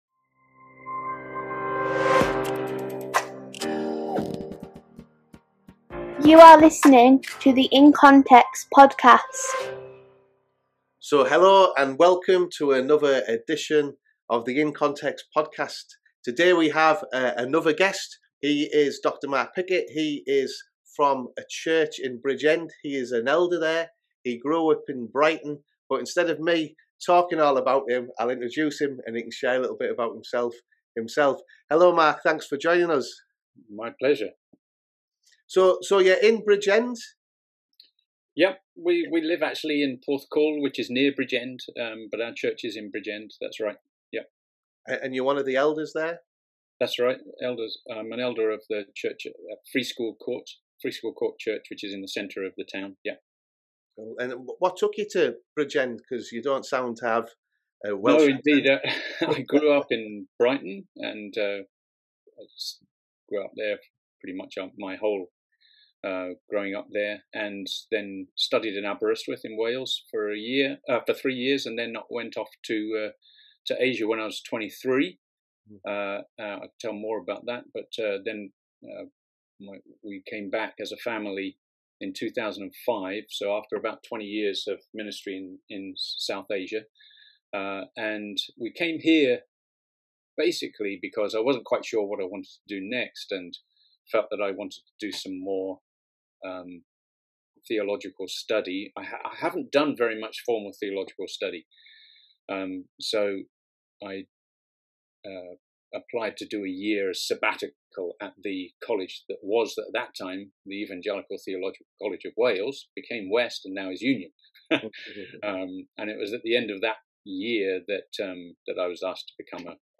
Working across cultures: An interview